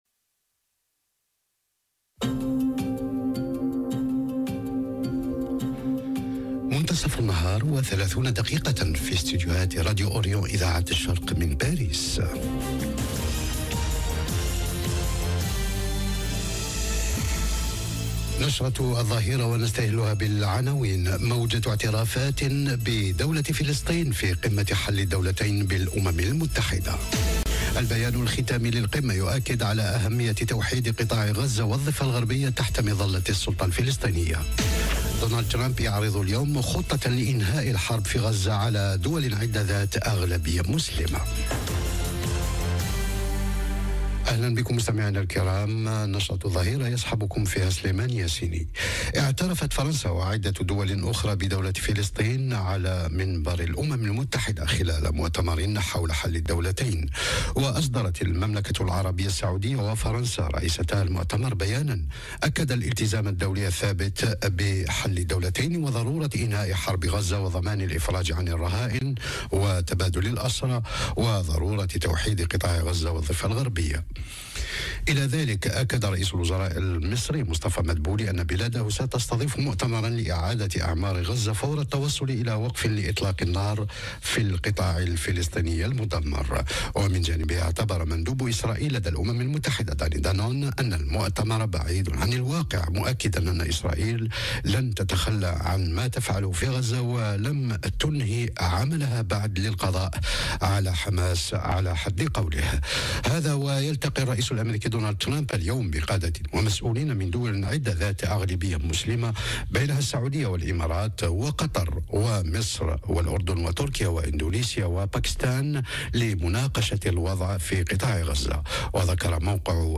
نشرة أخبار الظهيرة:البيان الختامي للقمة يؤكد على أهمية توحيد قطاع غزة والضفة الغربية تحت مظلة السلطة الفلسطينية - Radio ORIENT، إذاعة الشرق من باريس